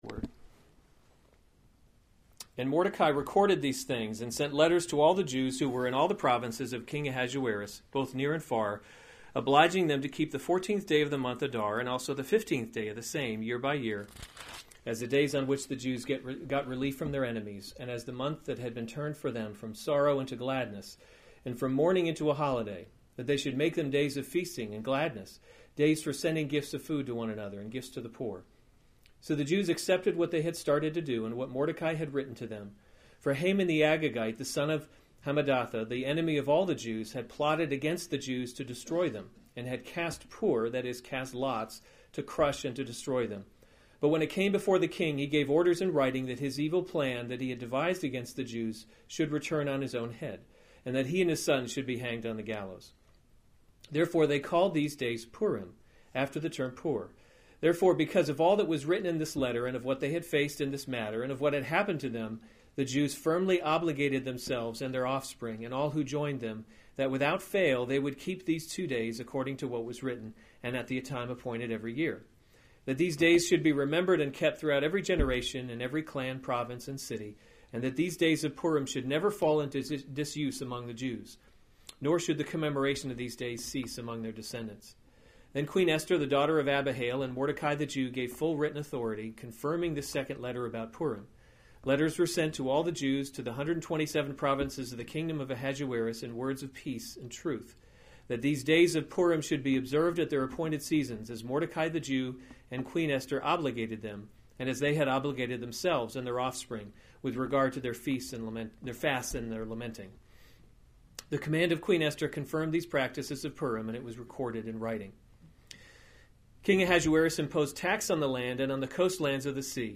January 28, 2017 Esther: God’s Invisible Hand series Weekly Sunday Service Save/Download this sermon Esther 9:20-10:3 Other sermons from Esther The Feast of Purim Inaugurated 20 And Mordecai recorded these things […]